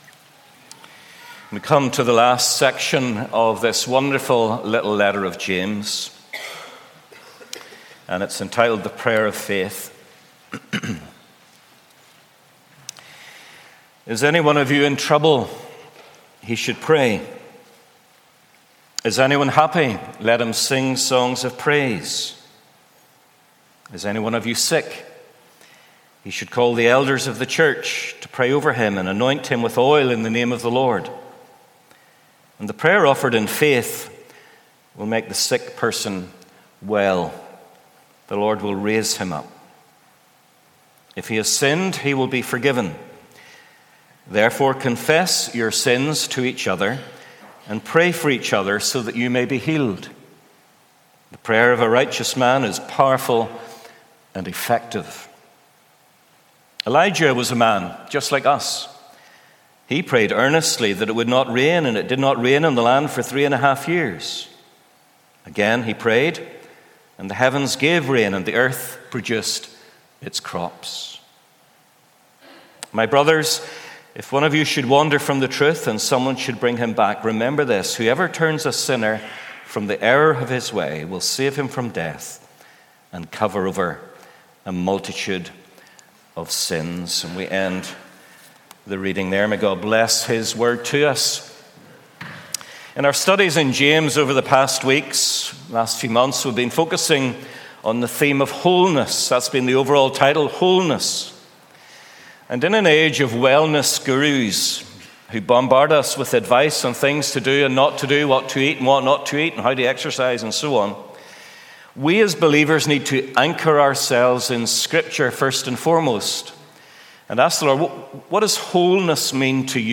Service Type: am